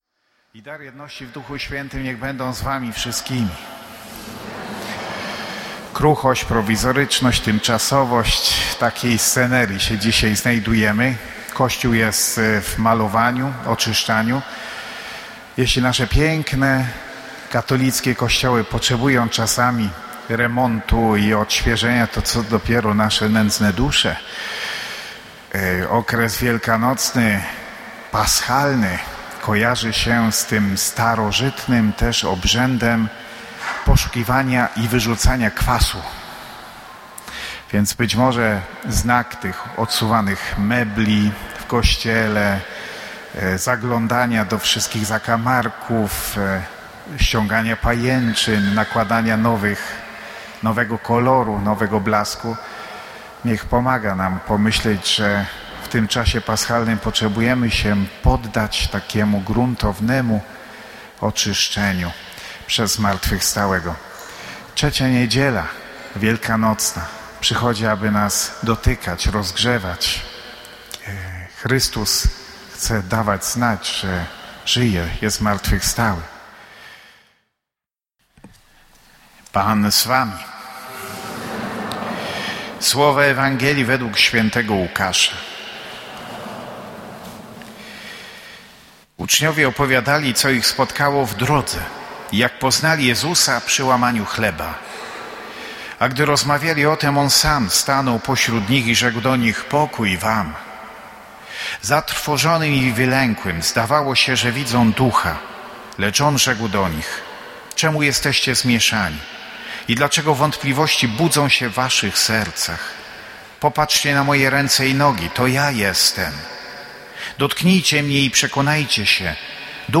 Kazania